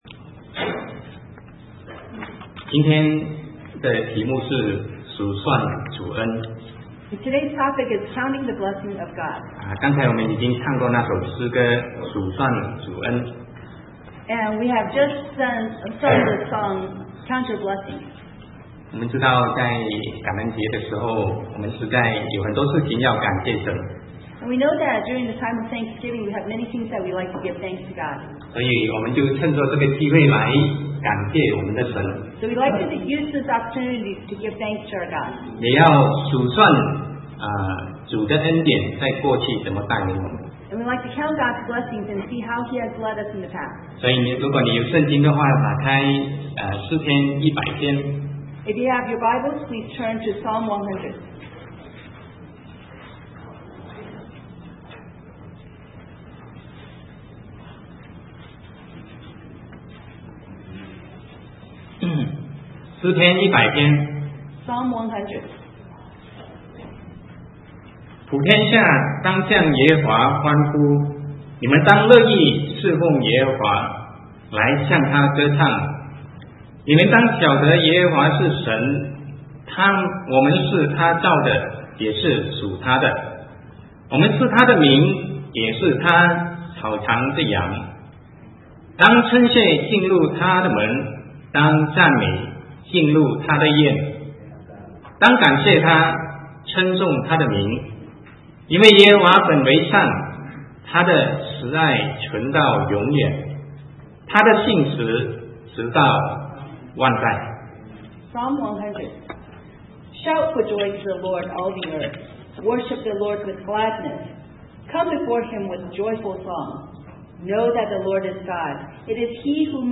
Sermon 2009-11-29 Counting the Blessing of God